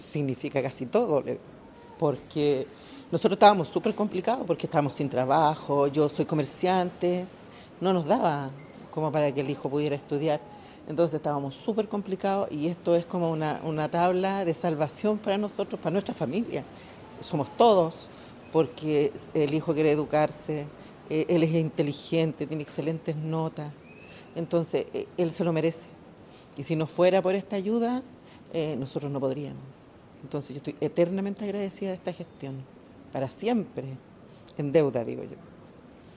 apoderada beneficiada, también compartió la importancia de poder contar con este beneficio